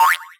miss.wav